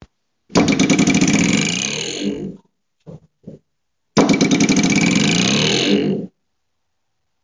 地基的声音 " 运动复合噪音 没有脚步声
描述：一个人走路的声音减去实际的脚步声。
标签： 运动 材料 金属 钥匙
声道立体声